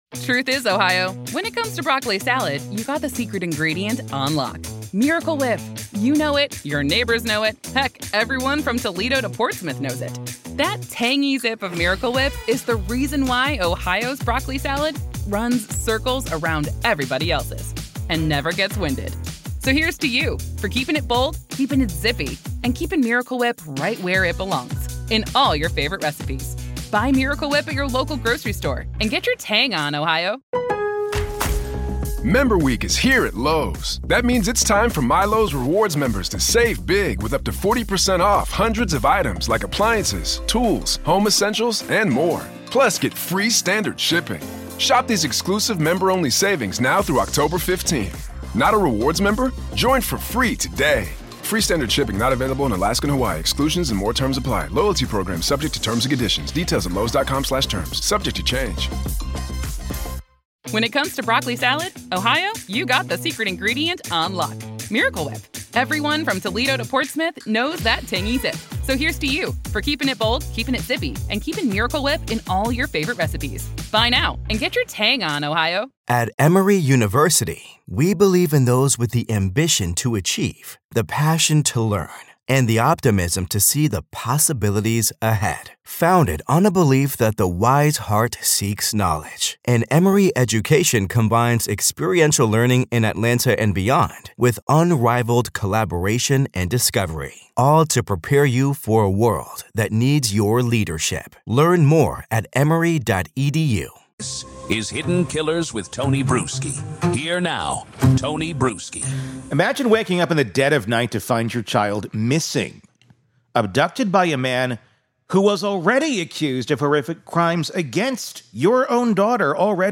In this conversation, we explore how this case has exposed systemic failures—from weak bail policies to baffling prosecutorial decisions. We look at Arkansas’s stand-your-ground and defense-of-others laws, and why they should apply.